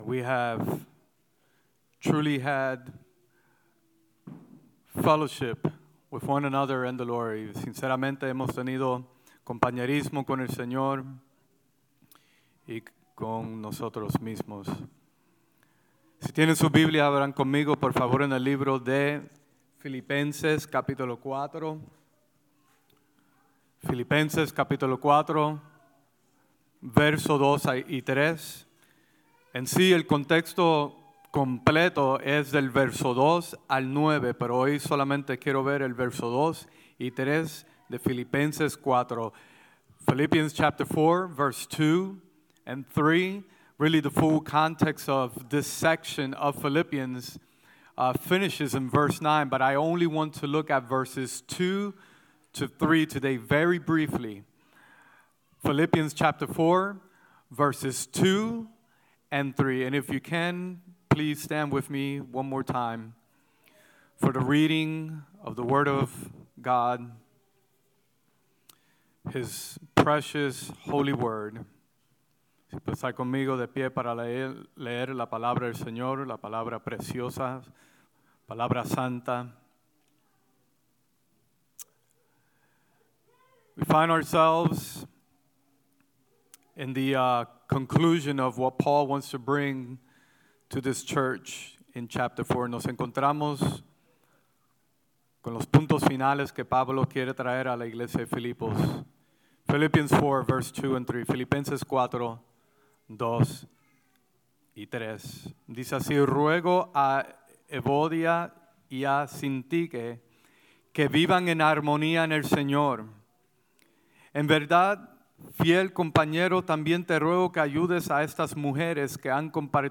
Sermons | Emmanuel Bible Church